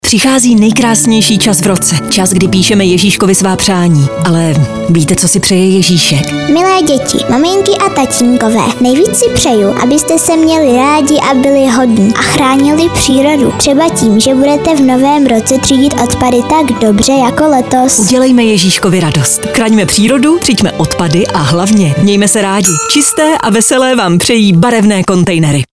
Rádio spot Vánoce